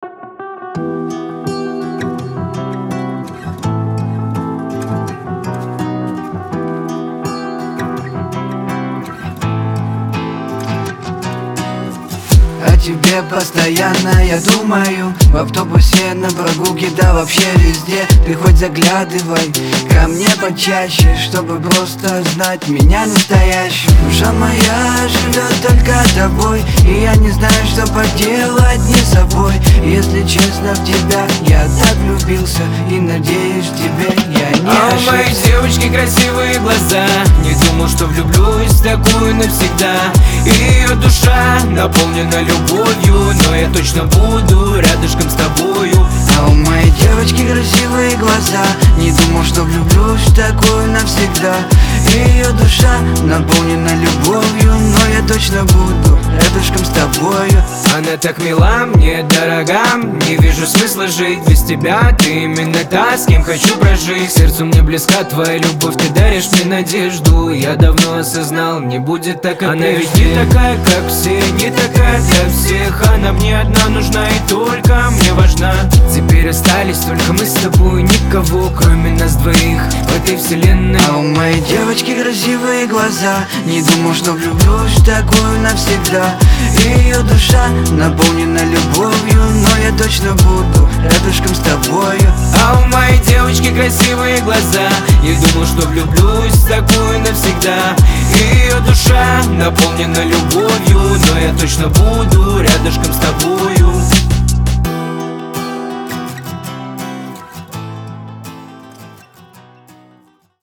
грусть
дуэт
ХАУС-РЭП